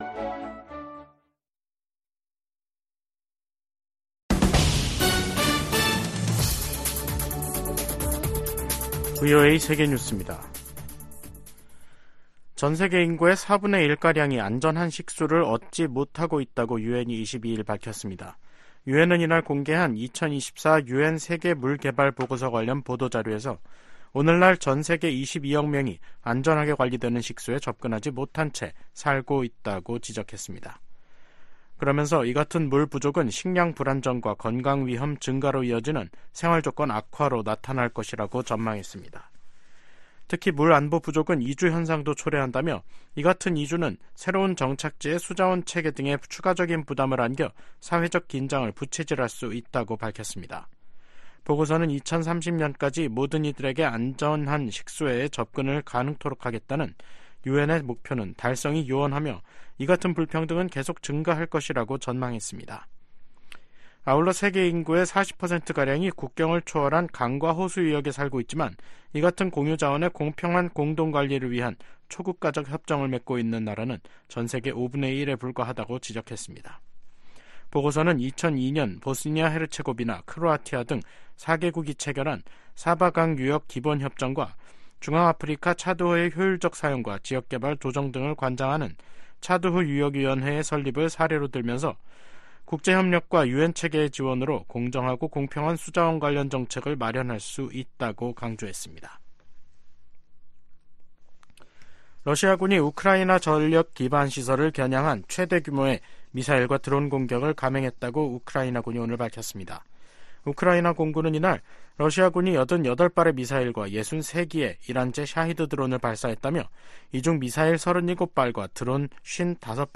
VOA 한국어 간판 뉴스 프로그램 '뉴스 투데이', 2024년 3월 22일 3부 방송입니다. 미국-한국 전시작전권 전환 작업이 완료를 위한 궤도에 있다고 폴 러캐머라 주한미군사령관이 밝혔습니다. 북한이 대륙간탄도미사일(ICBM)로 핵탄두를 미 전역에 운반할 능력을 갖췄을 것이라고 그레고리 기요 미 북부사령관 겸 북미 항공우주방위사령관이 평가했습니다. 윤석열 한국 대통령은 ‘서해 수호의 날'을 맞아, 북한이 도발하면 더 큰 대가를 치를 것이라고 경고했습니다.